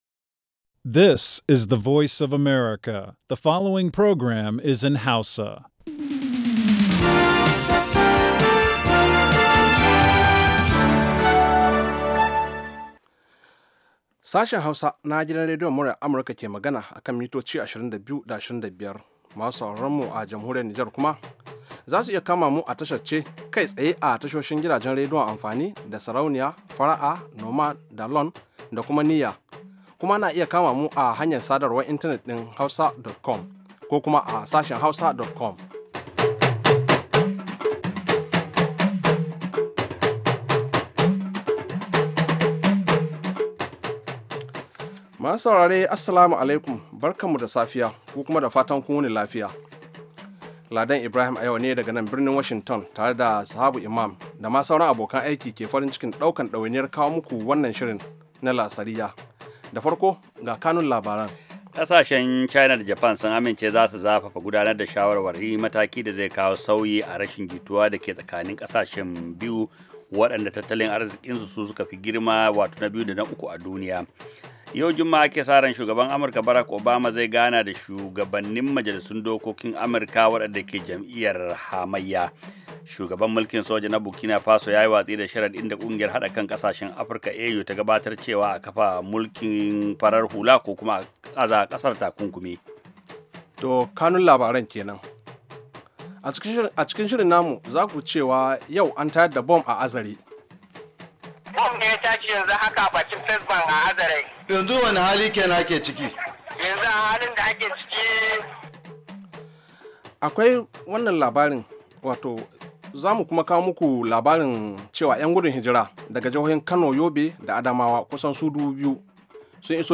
Da karfe 4 na yamma agogon Najeriya da Nijar zaku iya jin rahotanni da labarai da dumi-duminsu daga kowace kusurwa ta duniya, musamman ma dai muhimman abubuwan da suka faru, ko suke faruwa a kusa da ku a wannan rana.